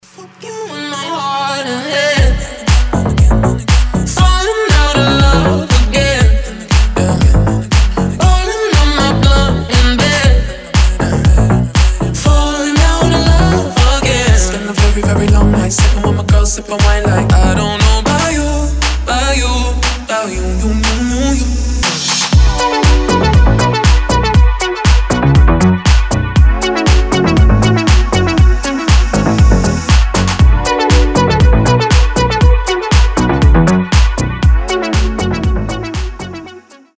• Качество: 320, Stereo
deep house
красивый мужской голос
заводные
басы
nu disco
Indie Dance